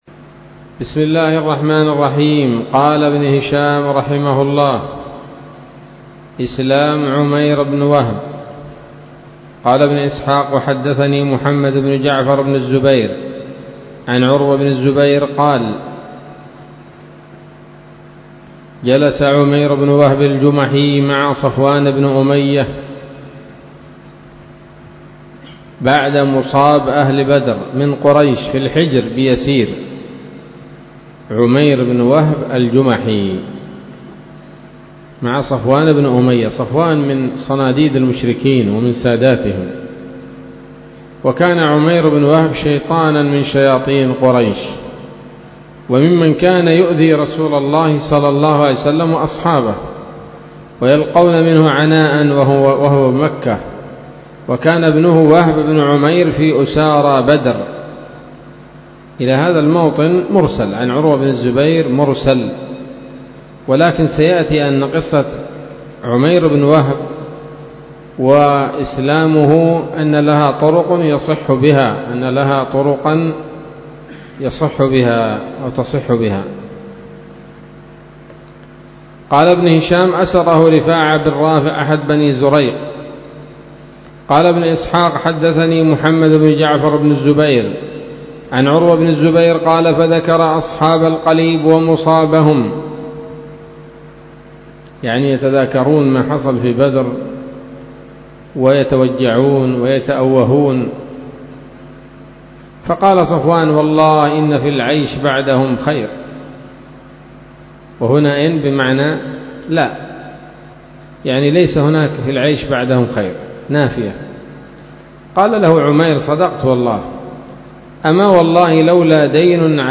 الدرس الثامن والعشرون بعد المائة من التعليق على كتاب السيرة النبوية لابن هشام